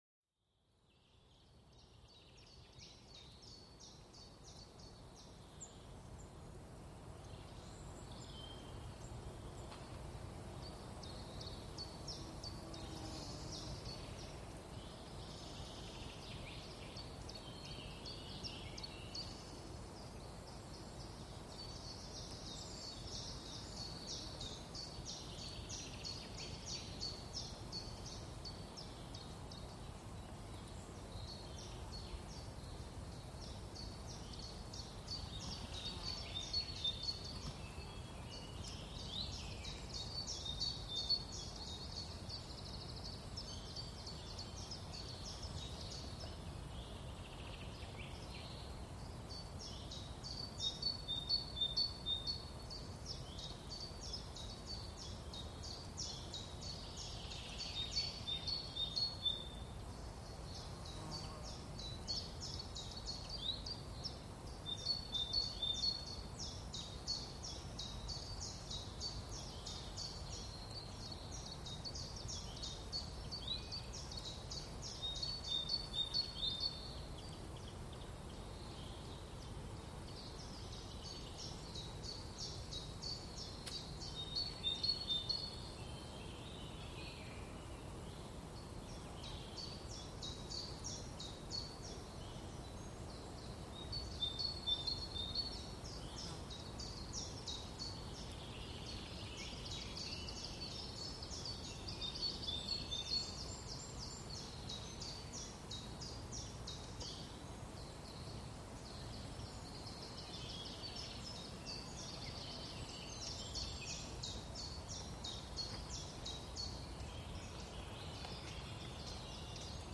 El canto de los pájaros para una relajación reconfortante
Sumérgete en la melodía de los pájaros, un concierto natural para el cuerpo y la mente.